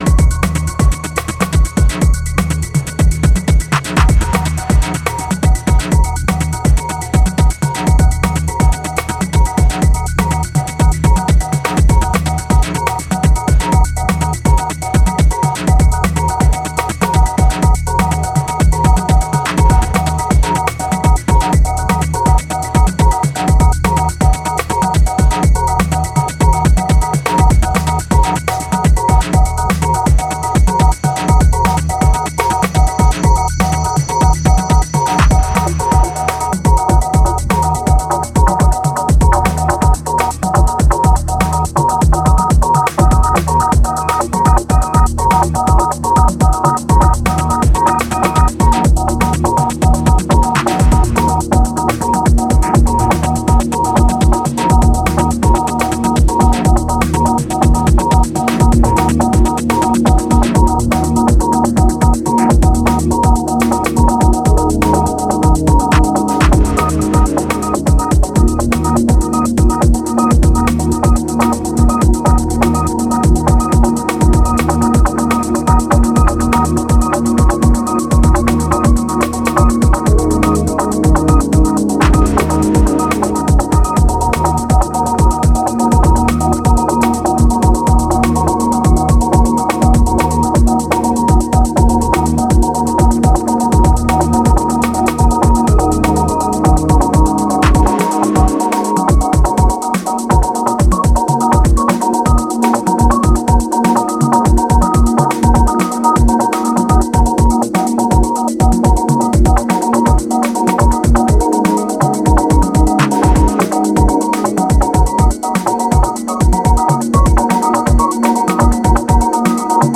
House Techno Chicago